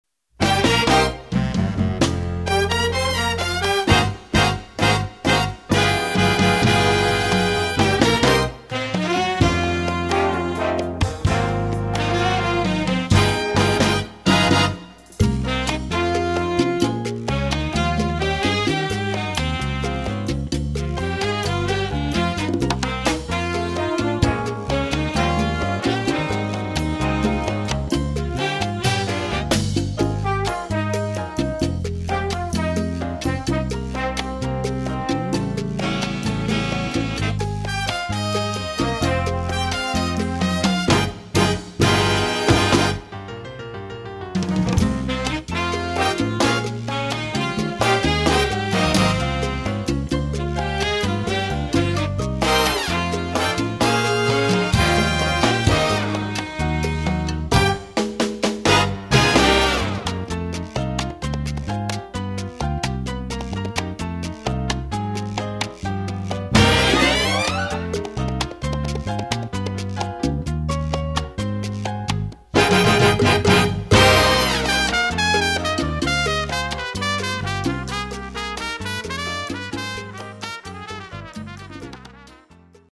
Category: big band
Style: cha cha
Instrumentation: big band (4-4-5, rhythm )